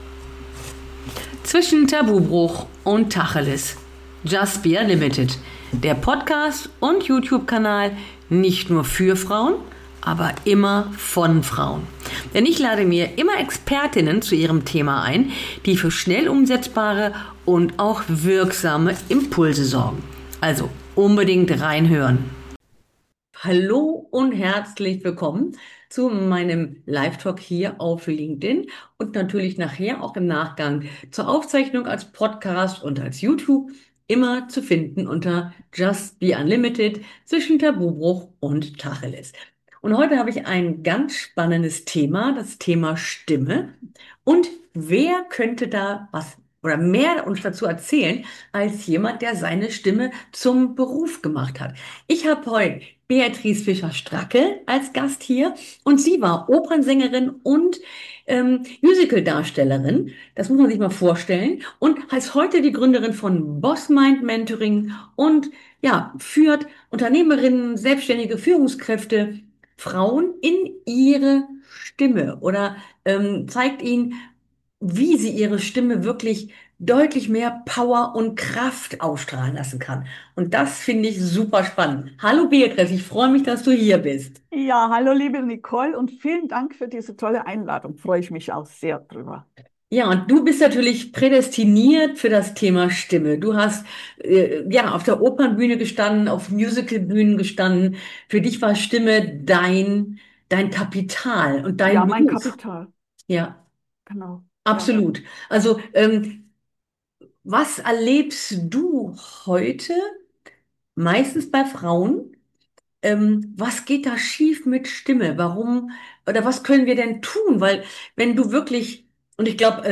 Hör also unbedingt die Aufzeichnung hier als Podcast an oder schaue das YouTube Video.